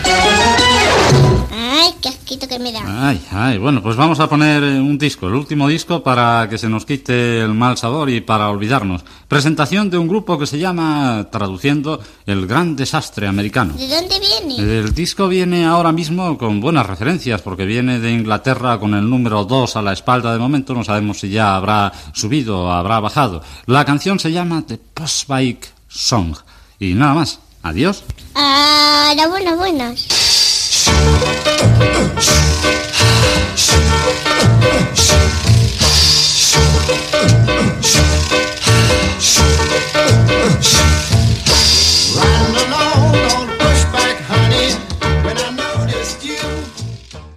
Presentació d'un tema musical i comiat